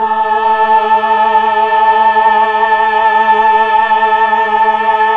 Index of /90_sSampleCDs/Keyboards of The 60's and 70's - CD1/VOX_Melotron Vox/VOX_Tron Choir